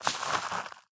Minecraft Version Minecraft Version latest Latest Release | Latest Snapshot latest / assets / minecraft / sounds / block / composter / ready4.ogg Compare With Compare With Latest Release | Latest Snapshot